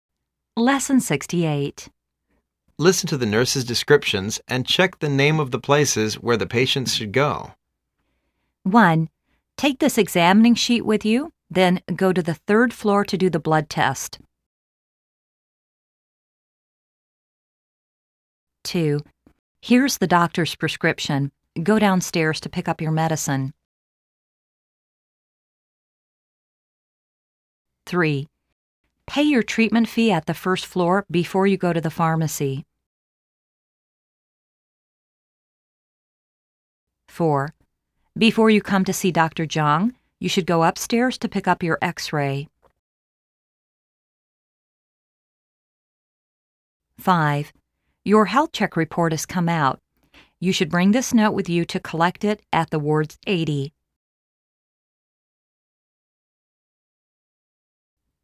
Listen to the nurse's descriptions and check the name of the places where the patient should go.